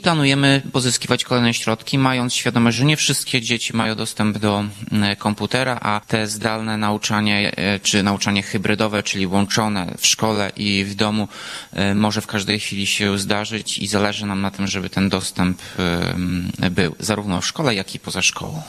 – To nie pierwsza i nie ostatnia dotacja na zakup sprzętu komputerowego dla uczniów miejskich szkół – mówi prezydent Ełku Tomasz Andrukiewicz.